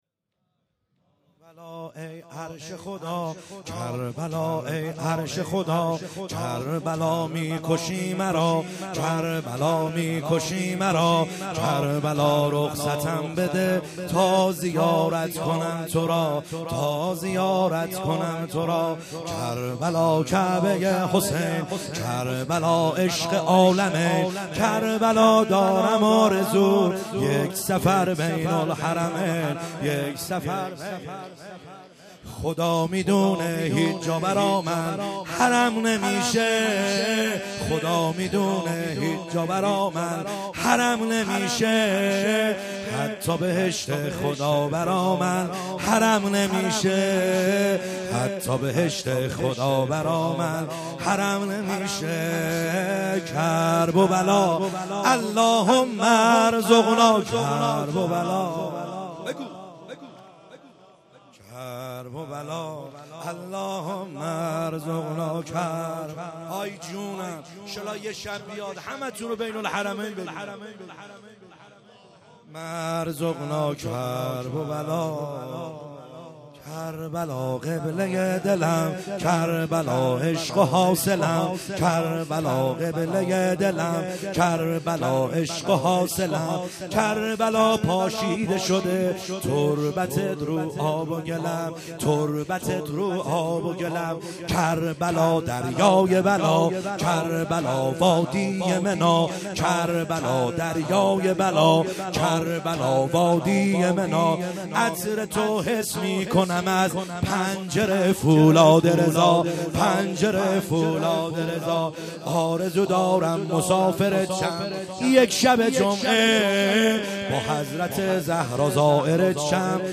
خیمه گاه - بیرق معظم محبین حضرت صاحب الزمان(عج) - واحد | کربلا ای عرش خدا